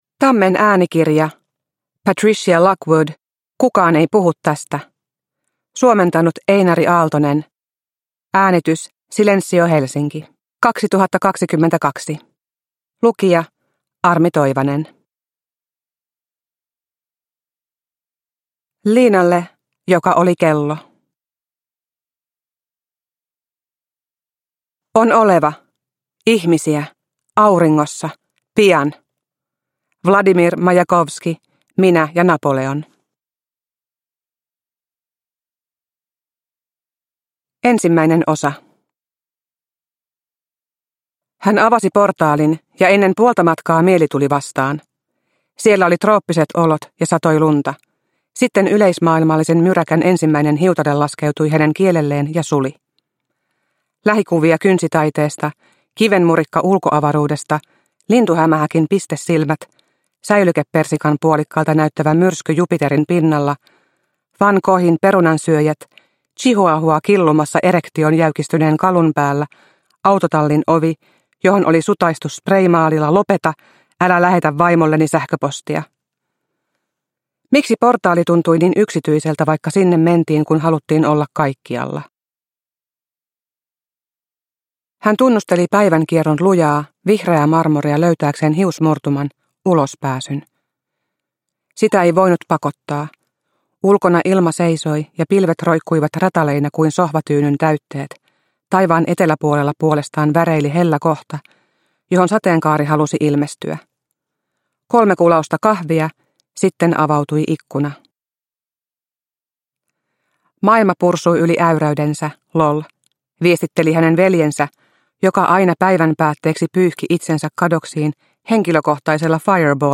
Kukaan ei puhu tästä – Ljudbok – Laddas ner